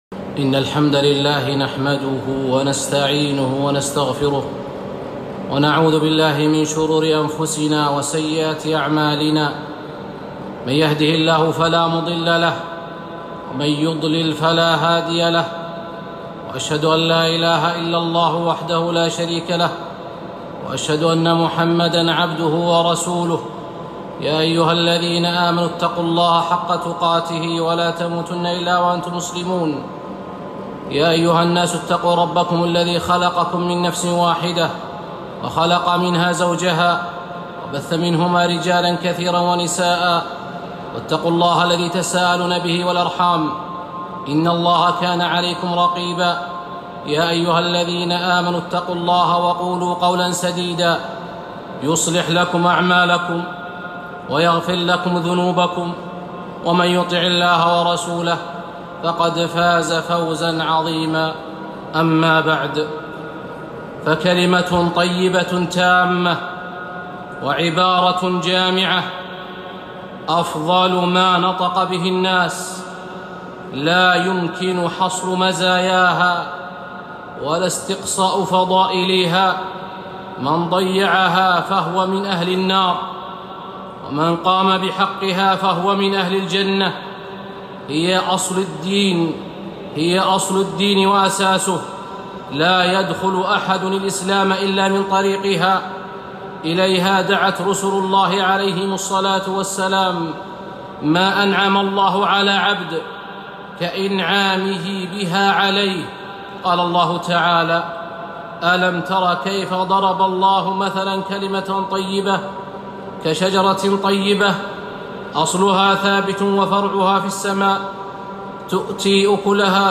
خطبة - (لا إله إلا الله) 9-6-1442